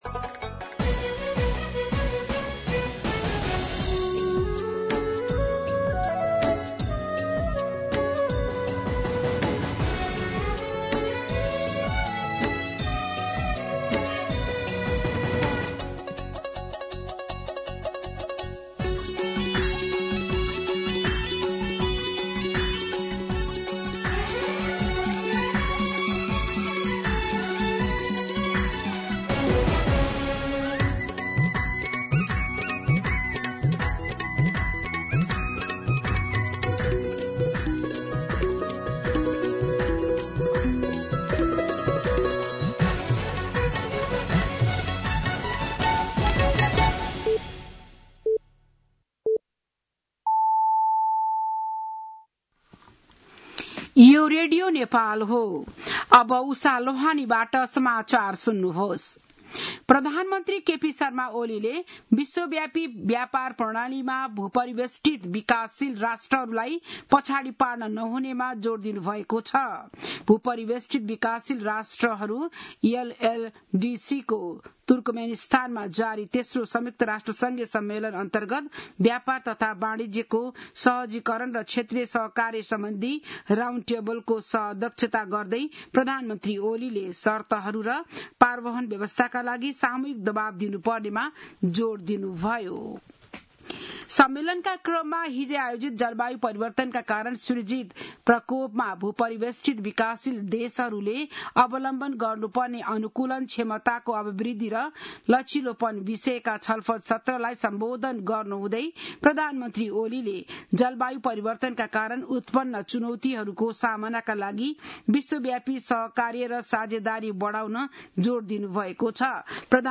बिहान ११ बजेको नेपाली समाचार : २२ साउन , २०८२
11-am-Nepali-News-2.mp3